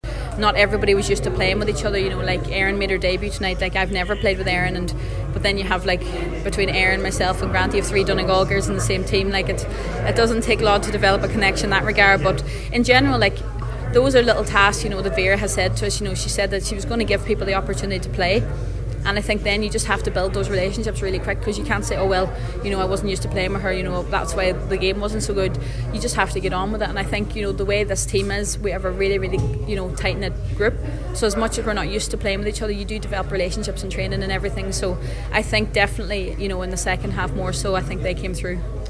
Barrett spoke to the media afterwards: